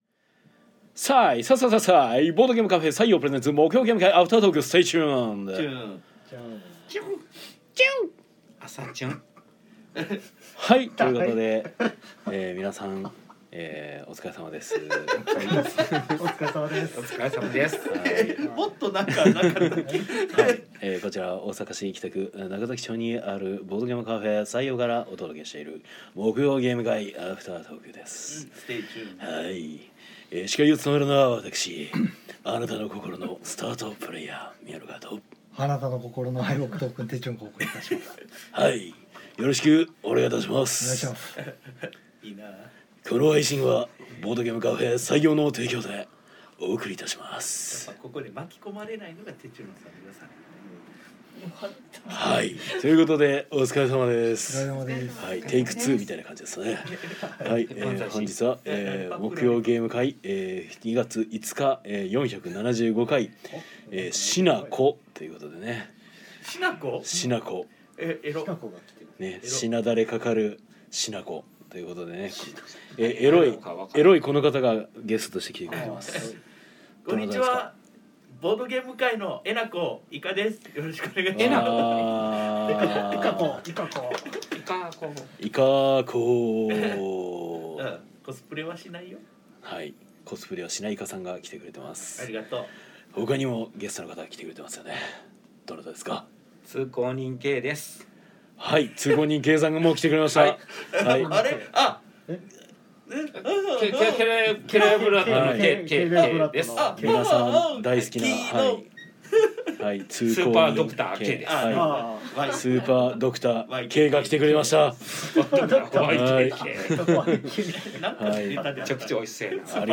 ゲーム会の話や、近況などをダラダラと生配信で垂れ流したものを鮮度そのままノーカットでパッケージング！（podcast化）